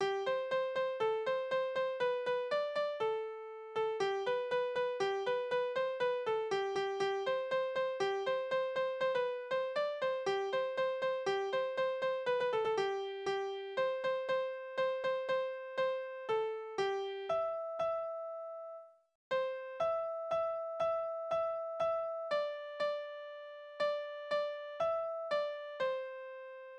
Kinderlieder
Tonart: C-Dur
Taktart: 4/8, 2/4
Tonumfang: große Sexte
Besetzung: vokal